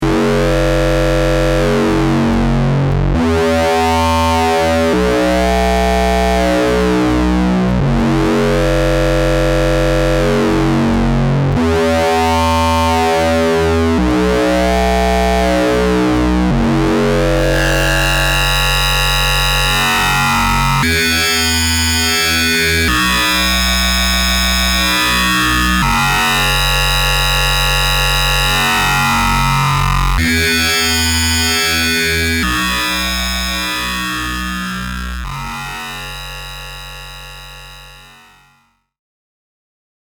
sample three: distortion circuit. standard monosynth input.